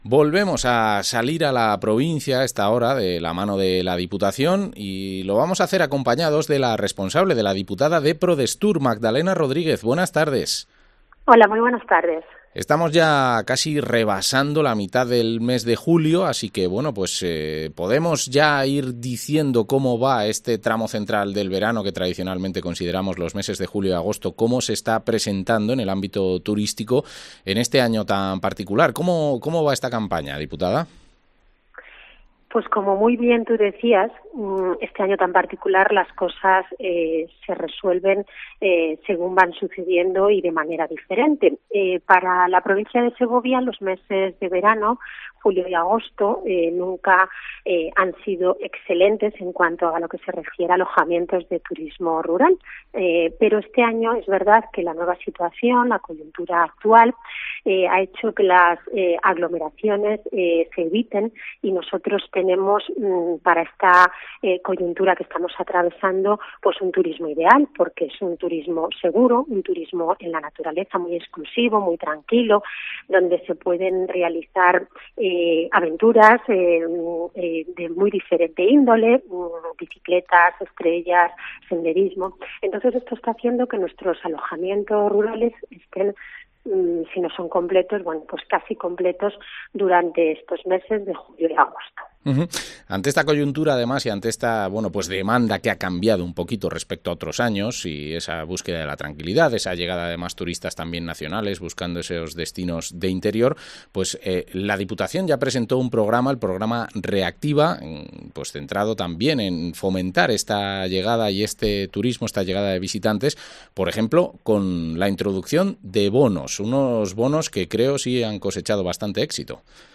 Entrevista a la diputada de Prodestur, Magdalena Rodríguez